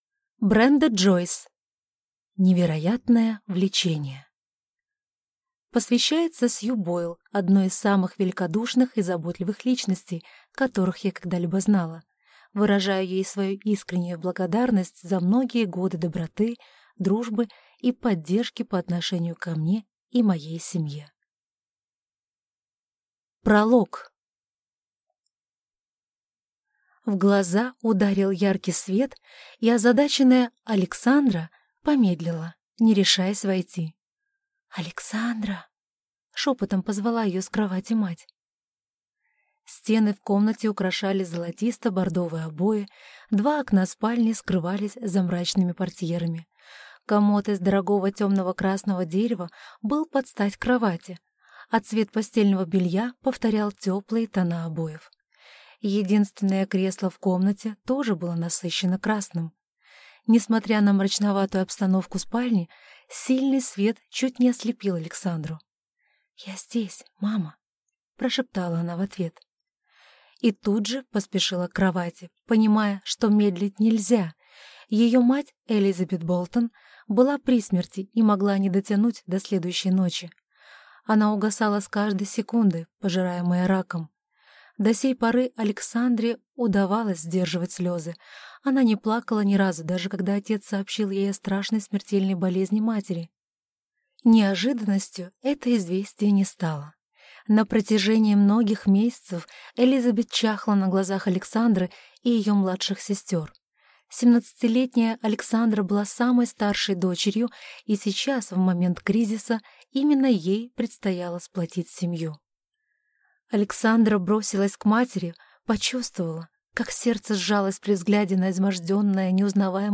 Аудиокнига Невероятное влечение | Библиотека аудиокниг